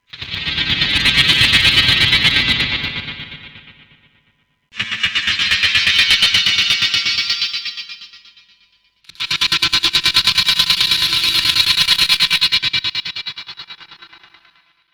Forme d'onde d'une table d'onde "Métal" (pas de noise, voir plus bas pourquoi).
Pour avoir du son de type "métal résonant dans un tube", cela ce passe avec un Delay "vintage à bande" + une large réverbe très "colorée".
Résultat 01 (100% au Virus TI):